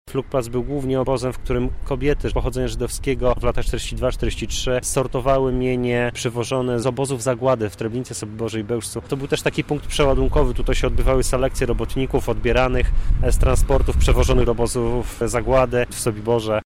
-mówi